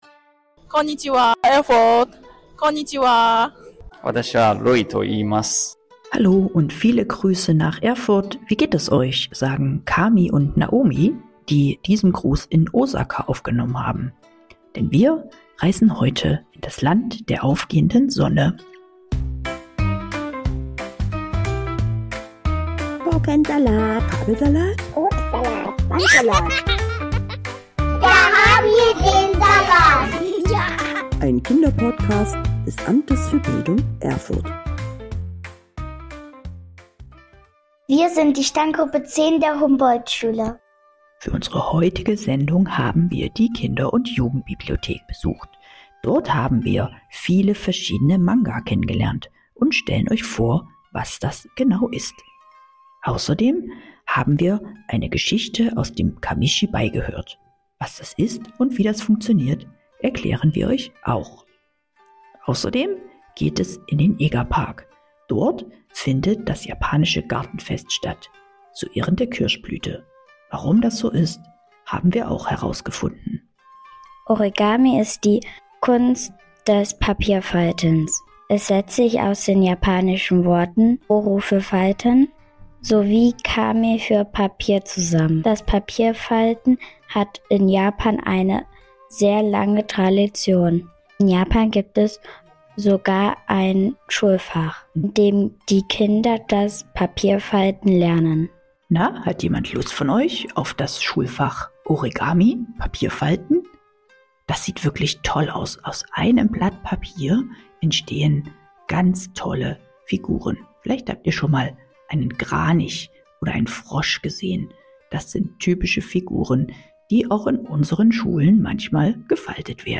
Auch wenn ihr wissen wollt, wie die japanischen Taiko-Trommeln klingen, solltet ihr in diese Folge hineinhören.